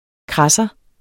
Udtale [ ˈkʁɑsʌ ]